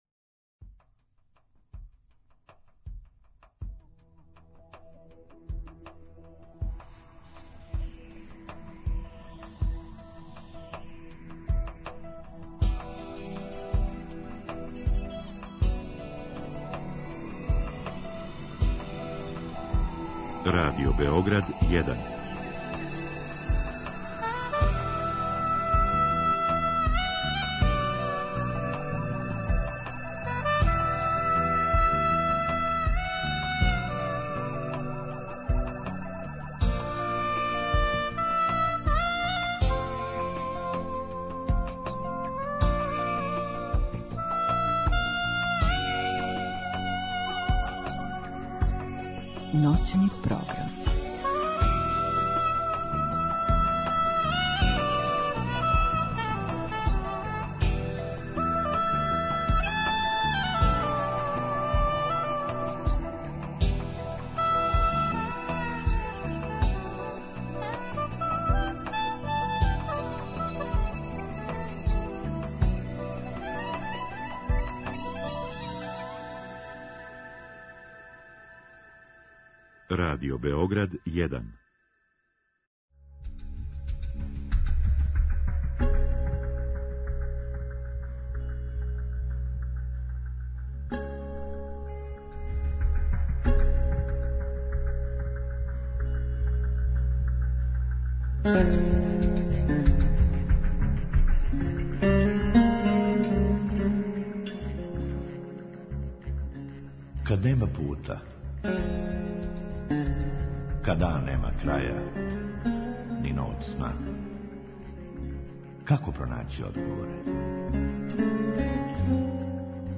У другом сату гошћи постављамо и питања слушалаца која су стигла путем наше Инстаграм странице.